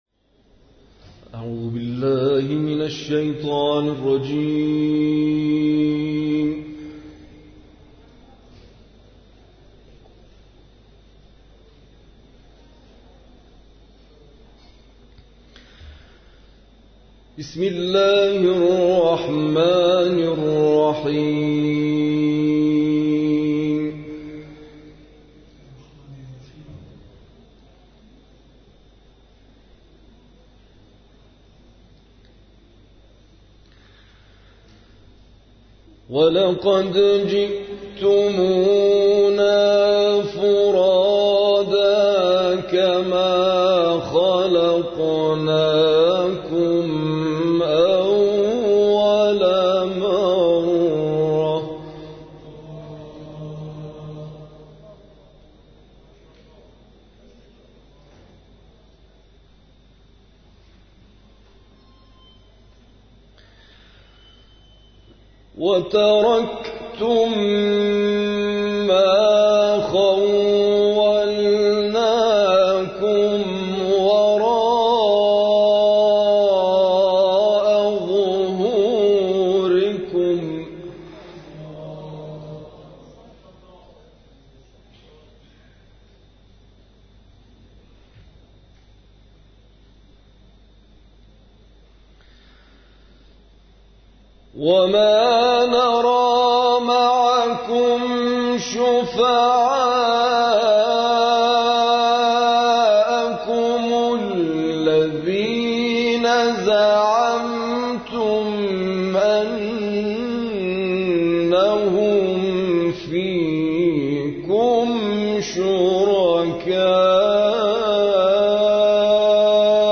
تلاوت سوره انعام
این تلاوت 31 دقیقه‌ای، هفته گذشته در مجمع قرآنی مسجد حضرت قائم(عج) شهر نوشهر اجرا شده است.